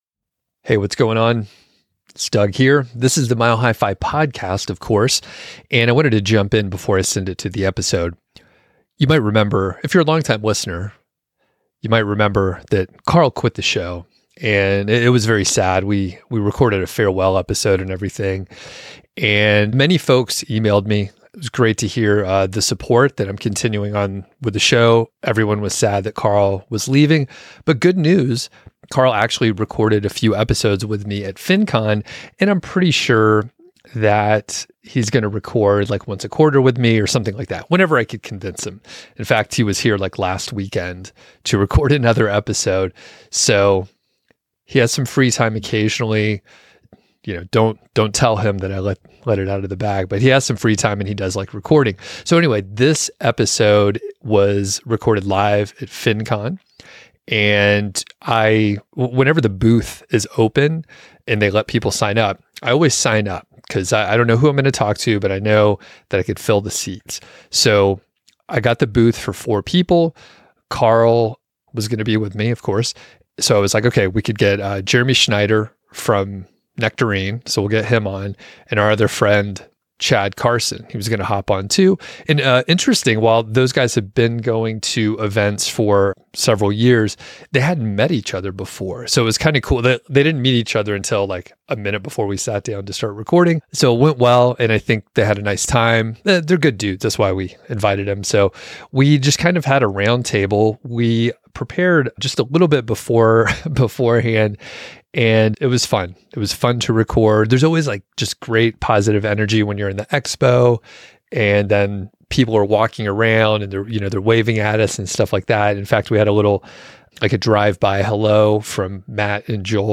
Roundtable
live from FinCon 2024 in Atlanta. The group discusses the benefits of financial independence, their unique entrepreneurial journeys, working after achieving FI, the current state of the FIRE movement, and the need for tension in life.